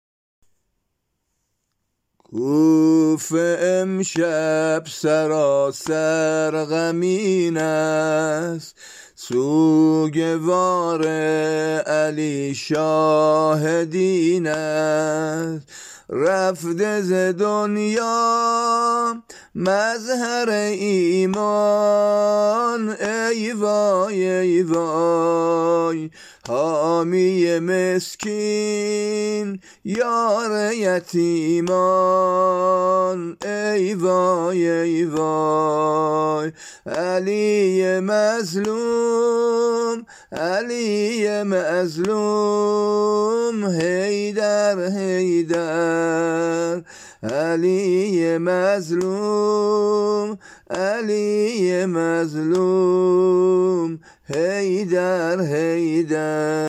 نوحه شهادت علی(ع)